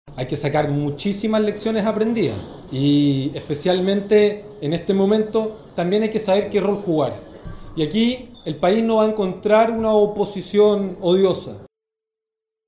El diputado Jorge Brito (FA) también valoró el ejercicio de reflexión de Jackson y llamó a que se extienda a más liderazgos y partidos del oficialismo.